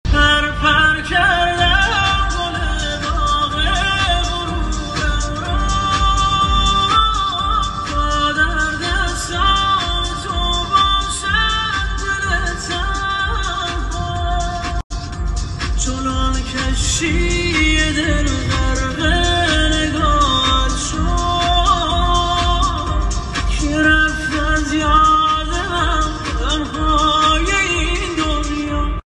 آهنگ جدید و غمگین